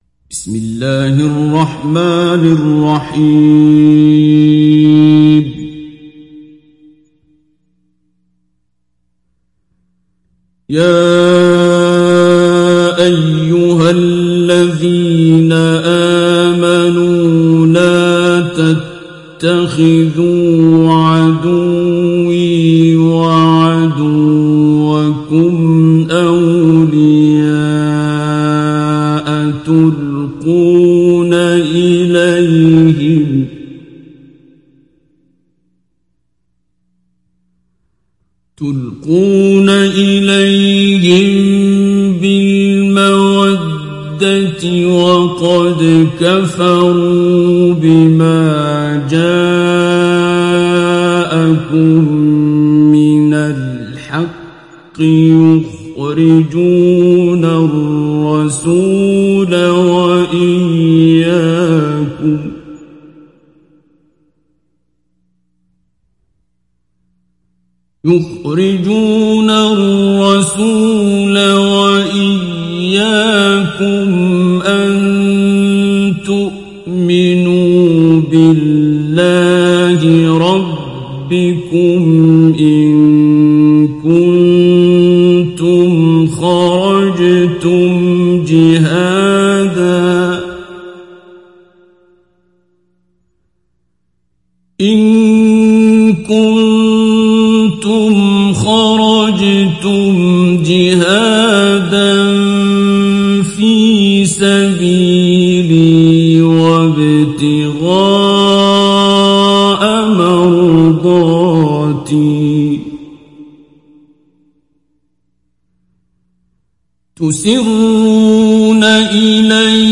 সূরা আল-মুমতাহিনা mp3 ডাউনলোড Abdul Basit Abd Alsamad Mujawwad (উপন্যাস Hafs)